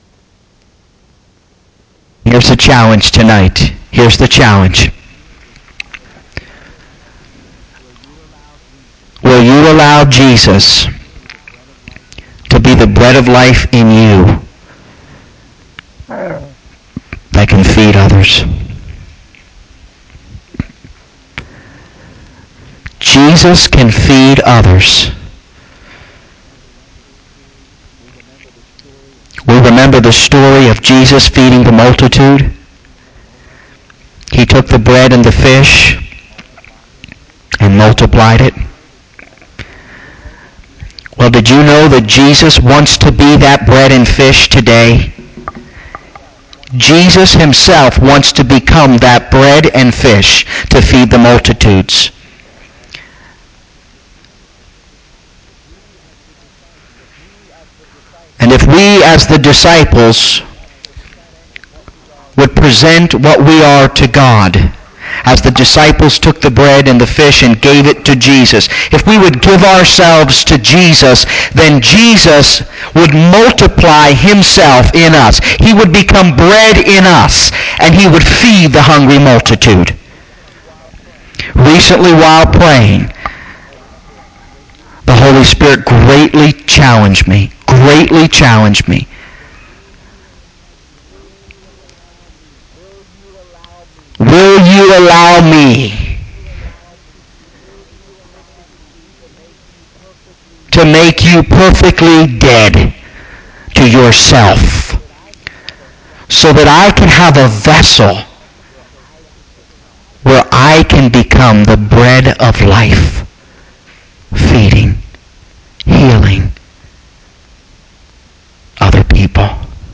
The sermon highlights the importance of continuous faith and relationship with Jesus, who is the true sustenance for our souls.